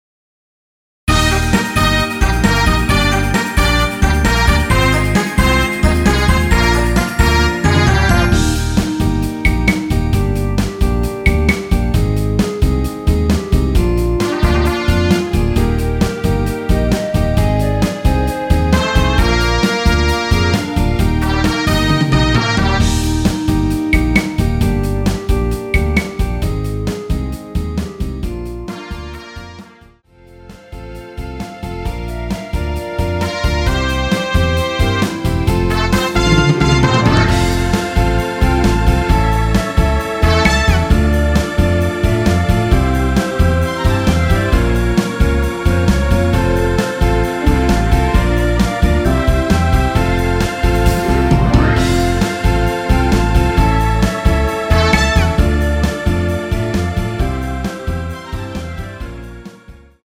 원키에서(-2)내린 멜로디 포함된 MR입니다.
Eb
앞부분30초, 뒷부분30초씩 편집해서 올려 드리고 있습니다.
중간에 음이 끈어지고 다시 나오는 이유는